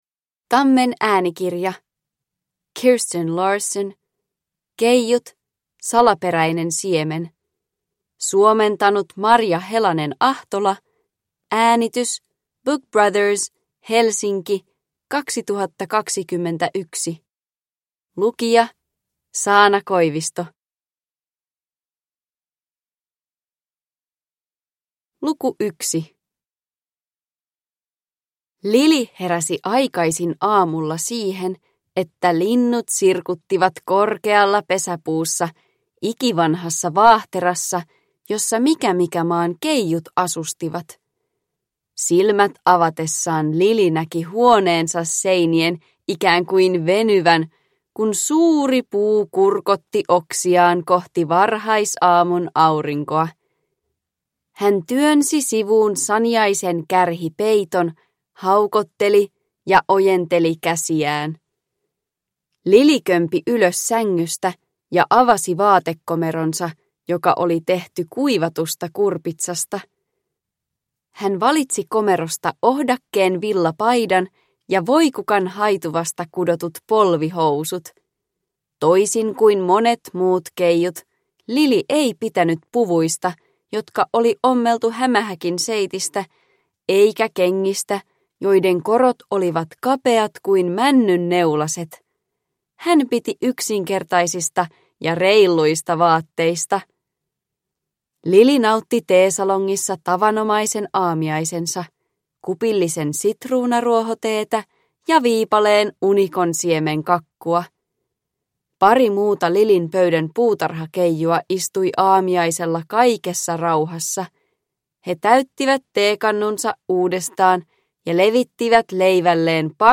Keijut. Salaperäinen siemen – Ljudbok – Laddas ner